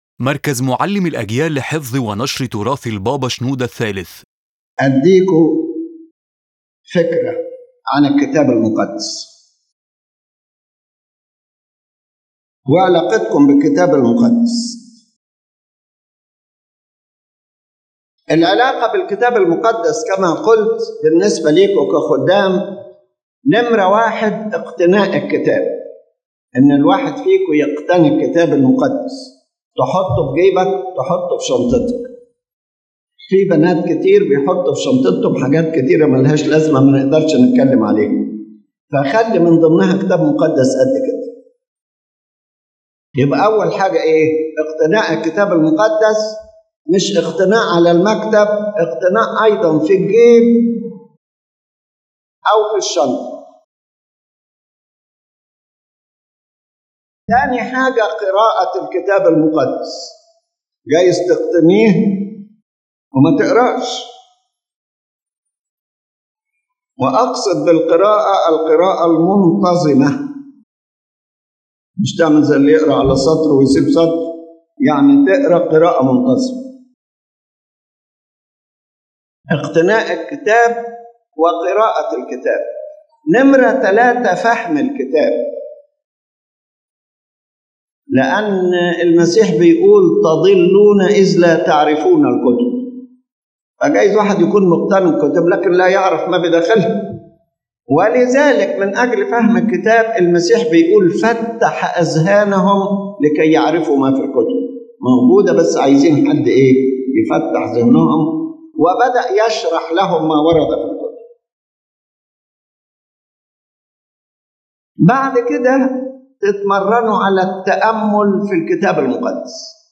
His Holiness Pope Shenouda III presents a practical and spiritual conception of the Holy Bible: how we possess it, read it, understand it, meditate on it, memorize it and act upon it, and why the Coptic Church gives a special liturgical and educational place to listening to, reading, and honoring the Word of God as a life guide and a living Spirit.